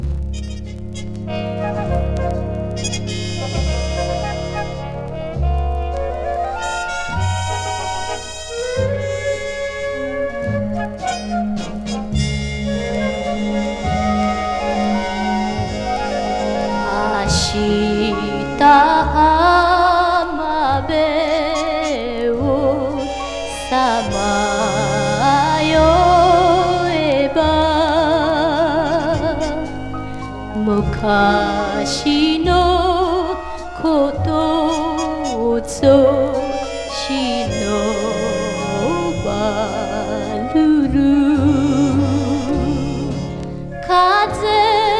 Jazz, Vocal　USA　12inchレコード　33rpm　Stereo